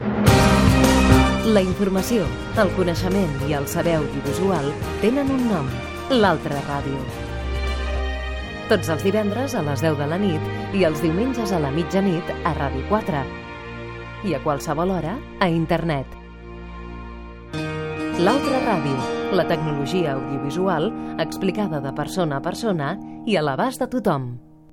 Promoció del programa L'altra ràdio